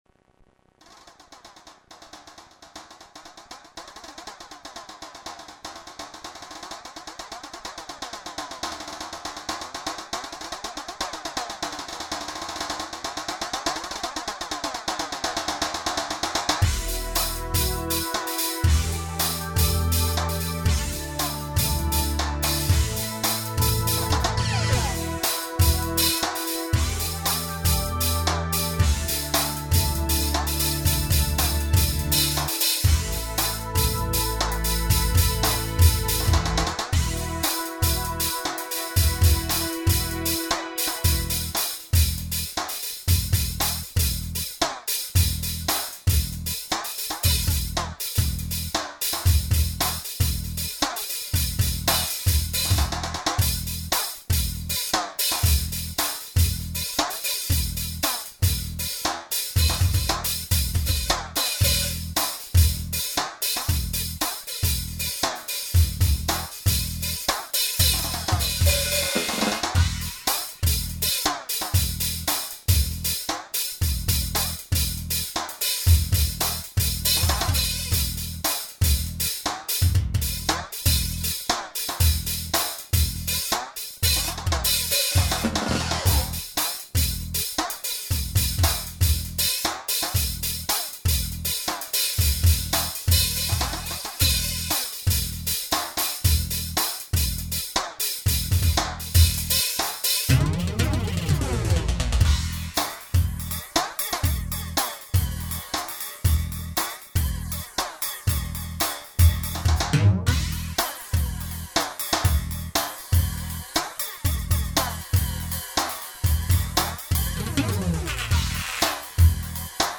Questo pezzo dalla sonorità molto particolare, realizzato con una versione personalizzata di un'impostazione base del drum kit, è caratterizzato da un suono elettronico filtrato da effetti "Flanger" e accompagnato da strings di tastiera midi che ne caratterizzano l'inizio e la fine.
La cassa è accompagnata da un basso e in diversi tratti sembra addirittura un pezzo completo, pieno, come se non mancassero altri strumenti di accompagnamento.
FlangerSounds.mp3